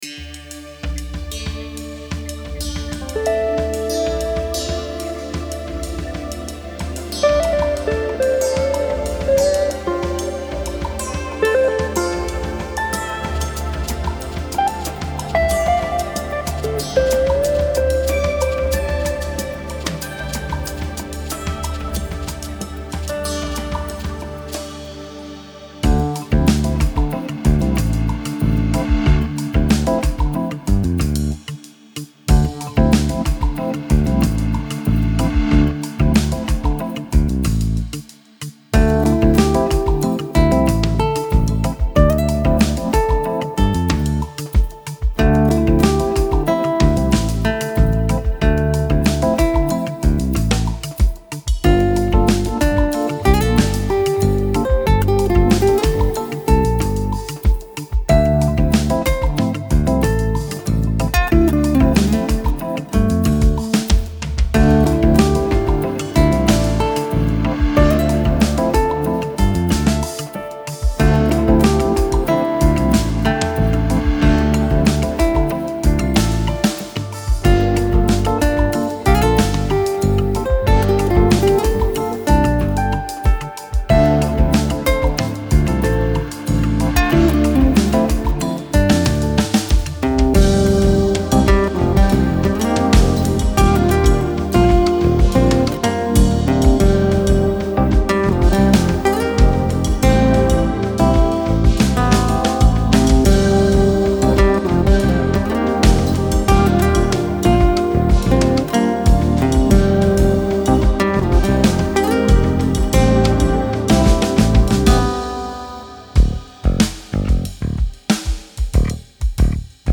PURPLE CITY (Instrumental